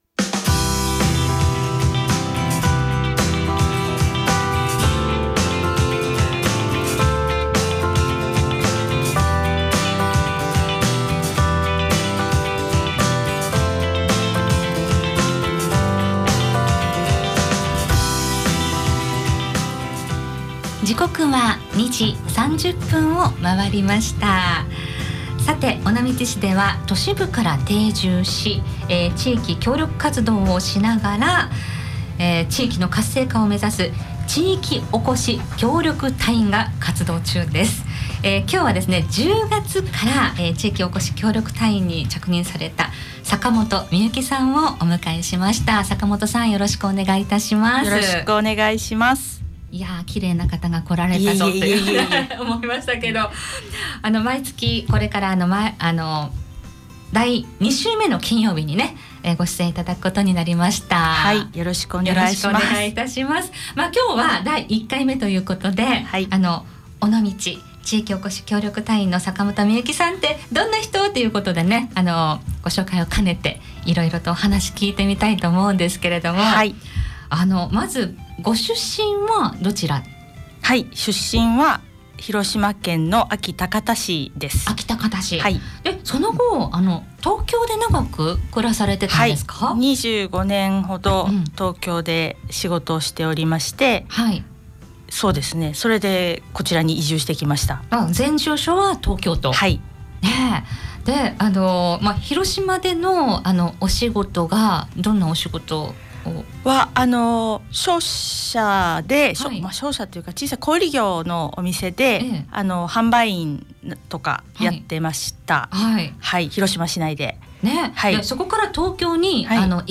尾道に移住した感想、今後に向けた意気込みなどをお聞きしました。